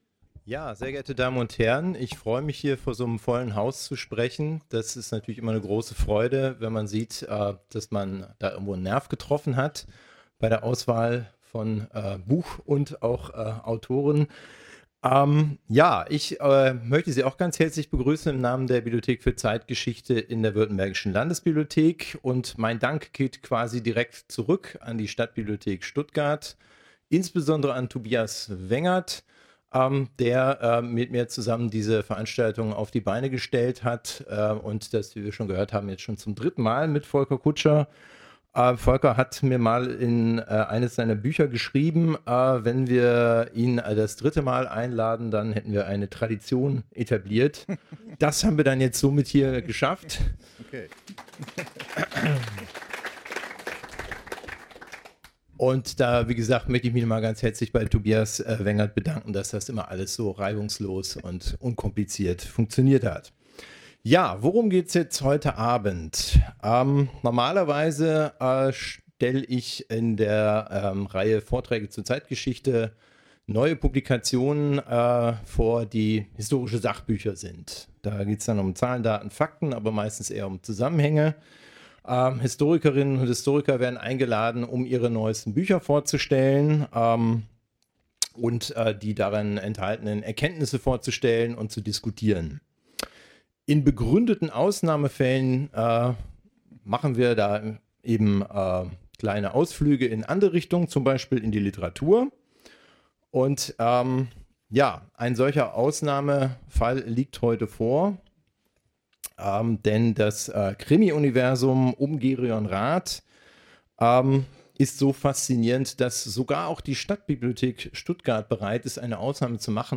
Gespräch
Veranstaltungen Stadtbibliothek Stuttgart